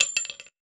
weapon_ammo_drop_08.wav